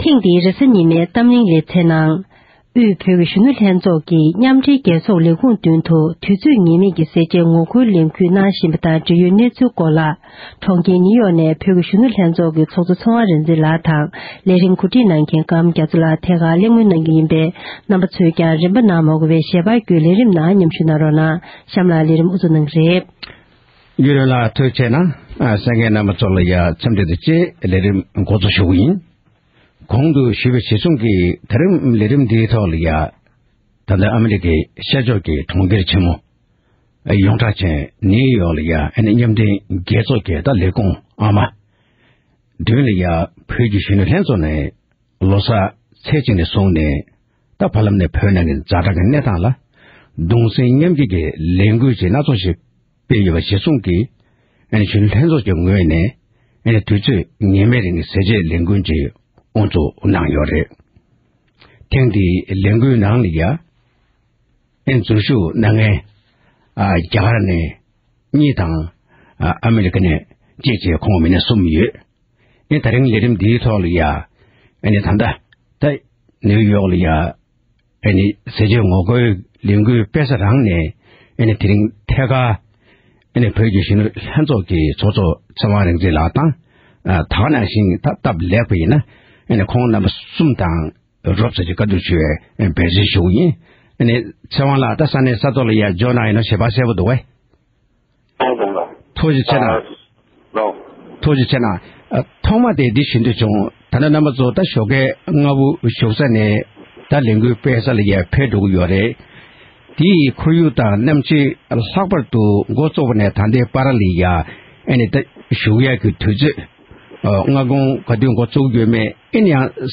དབར་གླེང་མོལ་གནང་བར་གསན་རོགས༎